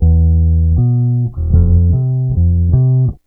BASS 34.wav